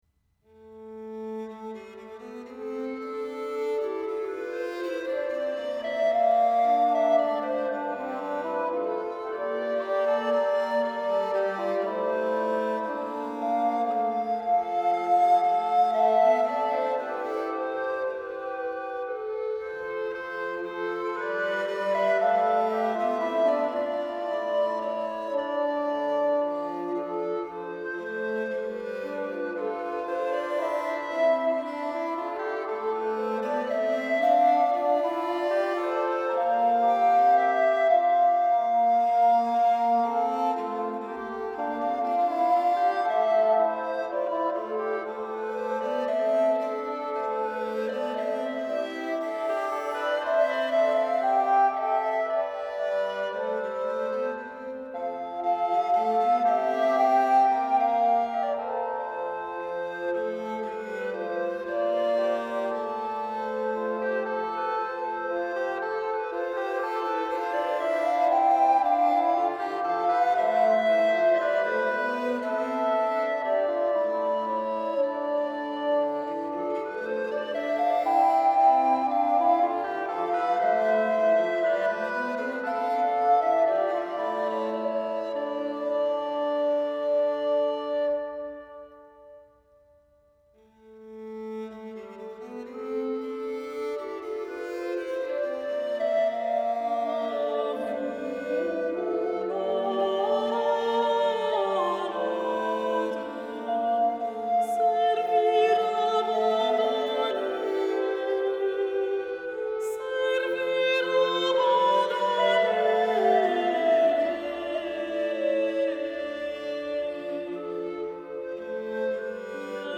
voice
recorders & claviciterium
lutes
douçaine
viols